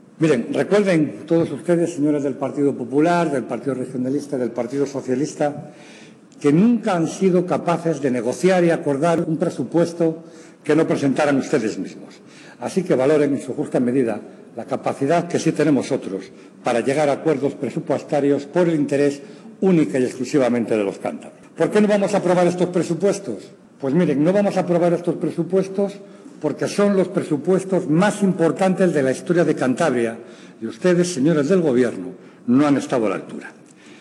Es lo que ha aseverado hoy el portavoz de Ciudadanos (Cs) en el Parlamento de Cantabria, Félix Álvarez, en su intervención en el debate del Proyecto de Ley de Cantabria de Presupuestos Generales para el año 2022.